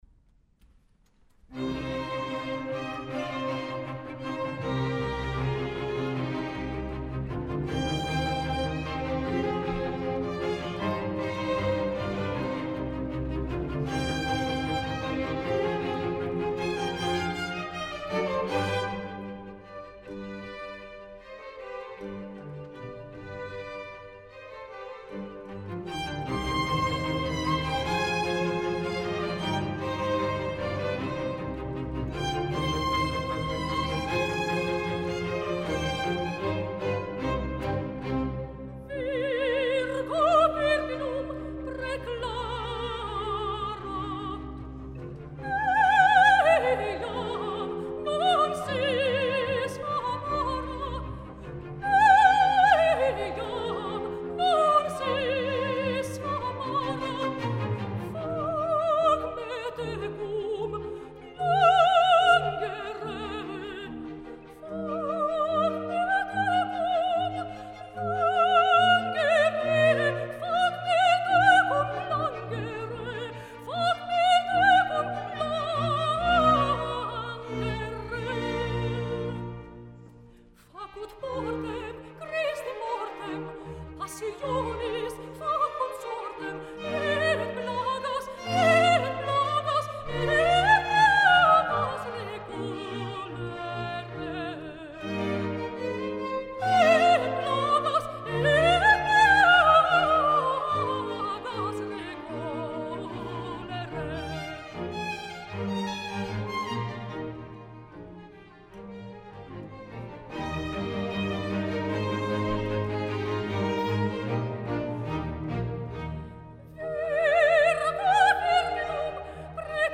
Přímý přenos Český rozhlas Vltava a síť Evropské vysílací unie.
Kostel sv. Šimona a Judy
Členky Dětského sboru Českého rozhlasu
Symfonický orchestr Českého rozhlasu